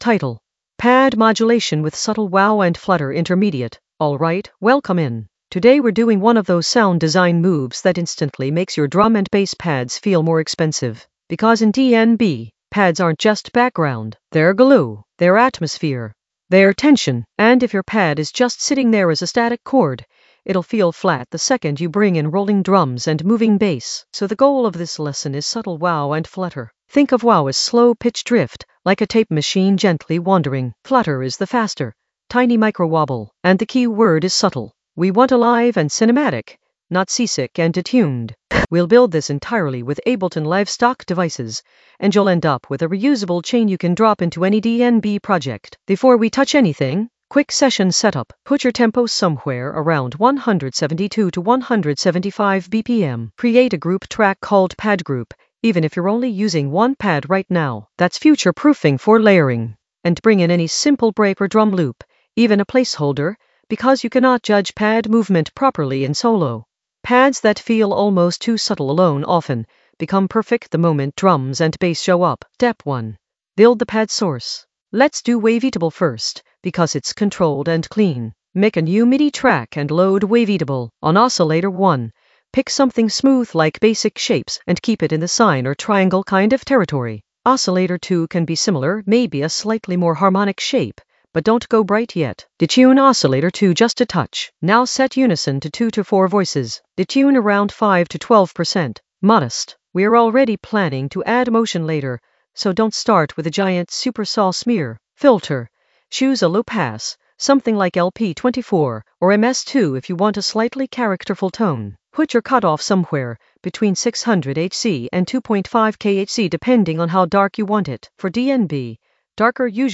Narrated lesson audio
The voice track includes the tutorial plus extra teacher commentary.
pad-modulation-with-subtle-wow-and-flutter-intermediate-sound-design.mp3